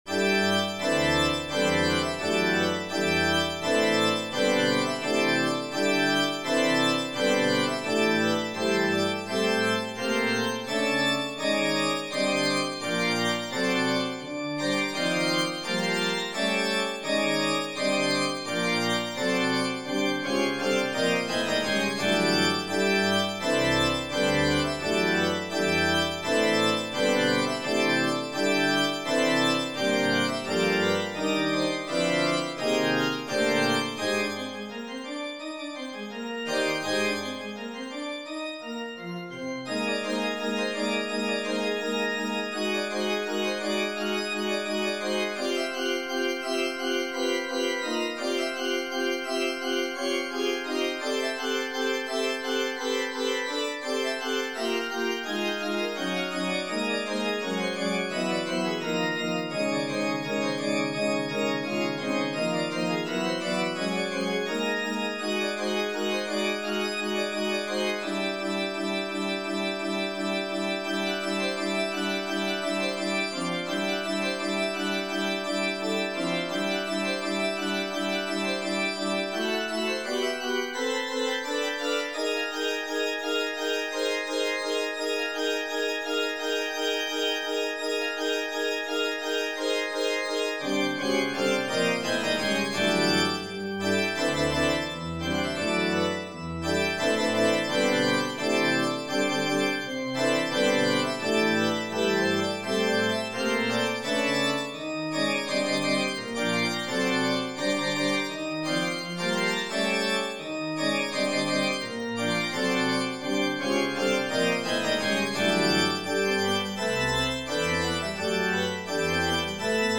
I have been messing around with making MP3s from notation files of my compositions.
Organ
Midi